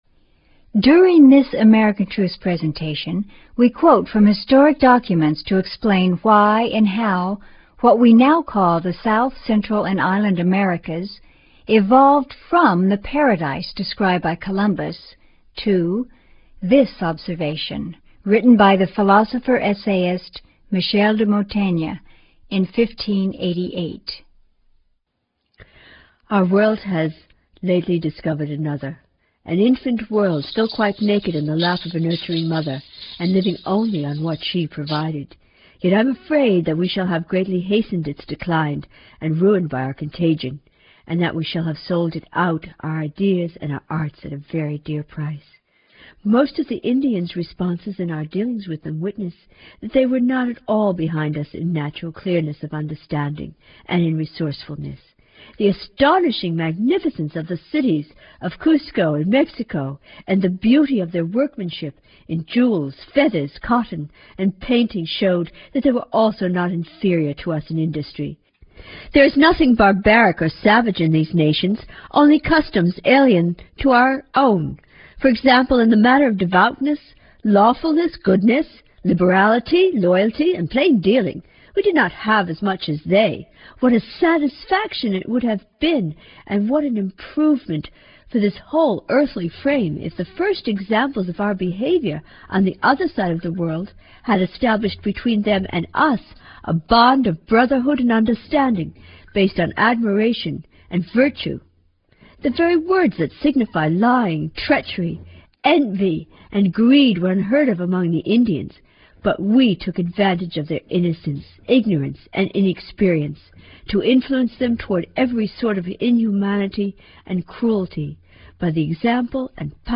All of these documented materials are dramatically presented with historically relevant music, and by four Speakers: